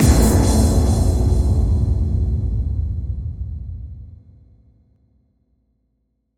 Index of /musicradar/cinematic-drama-samples/Impacts
Impact 02.wav